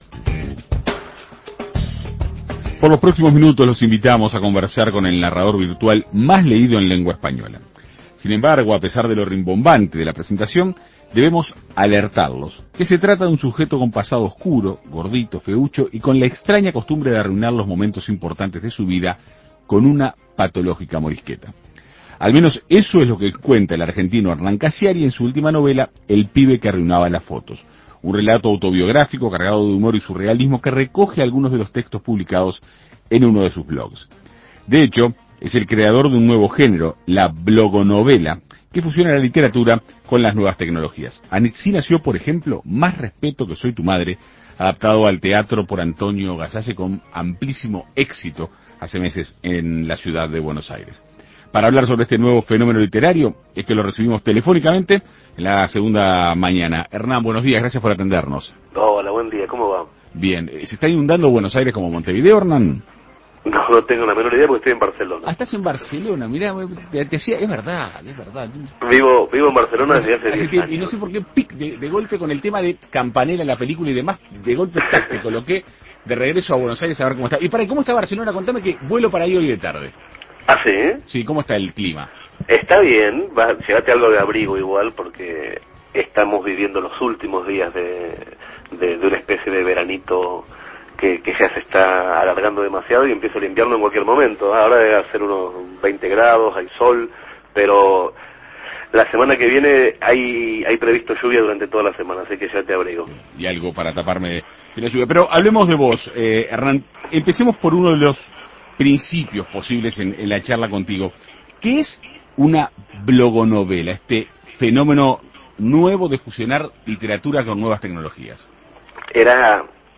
En Perspectiva Segunda Mañana dialogó con él para conocer los detalles de este nuevo fenómeno literario.